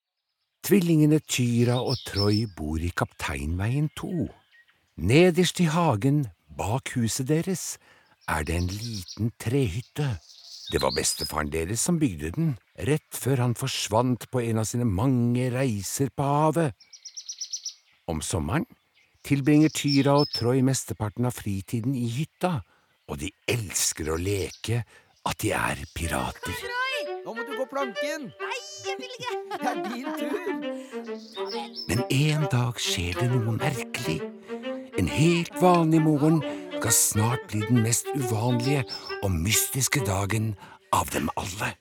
Jakten på den røde stjerne (lydbok) av H.L. Phoenix